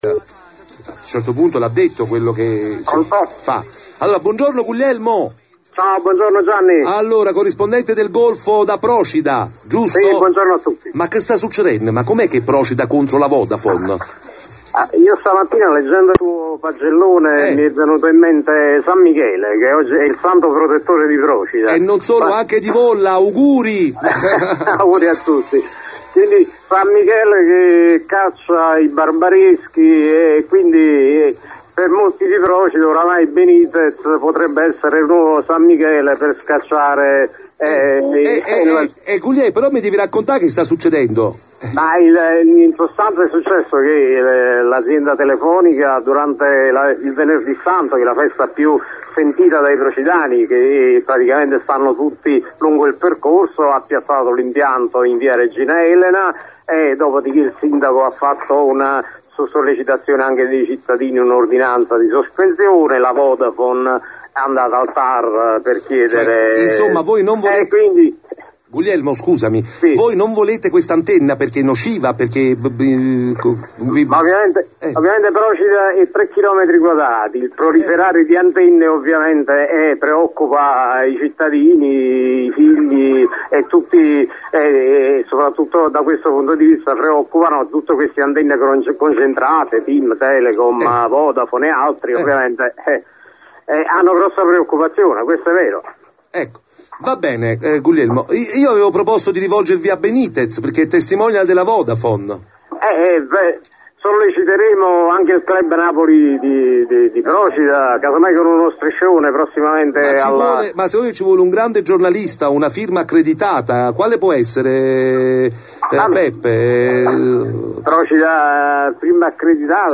in diretta dalle 10,30 su Radio Marte